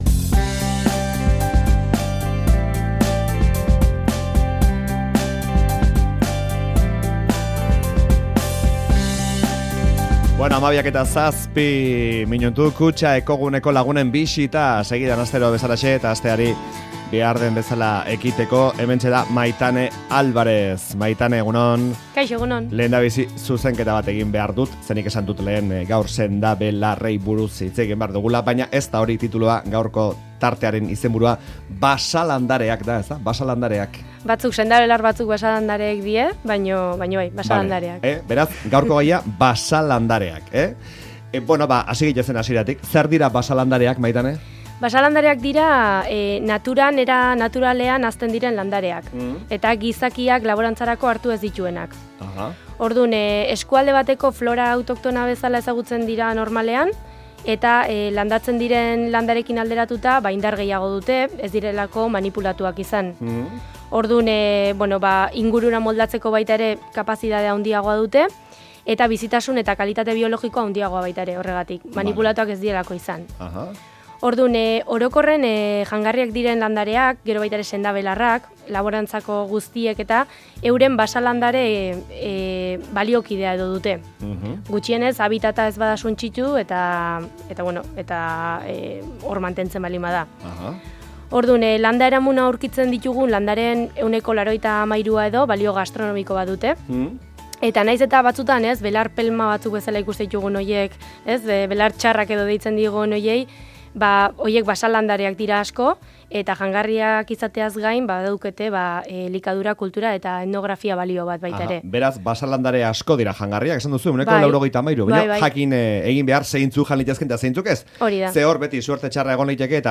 Astelehenero izaten ditugu Euskadi Irratiko Faktorian Kutxa Ekoguneko lagunak;